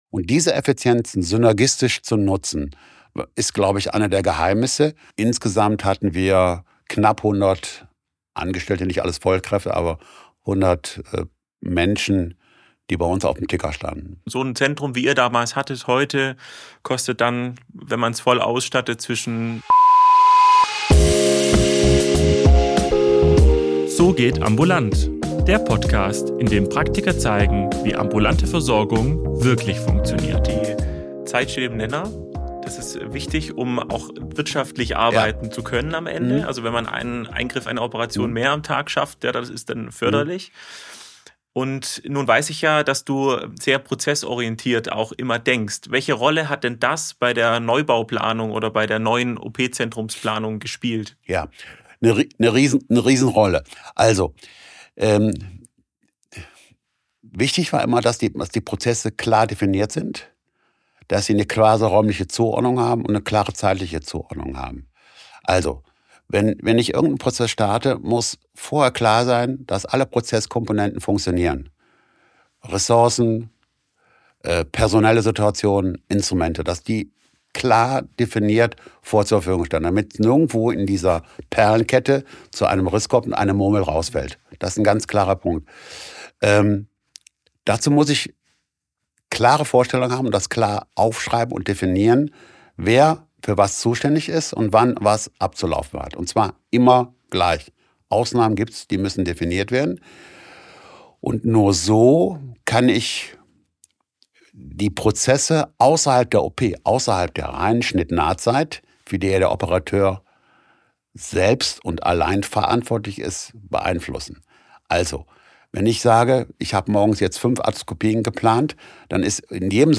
In Teil 2 dieses zweiteiligen Gesprächs erfährst du: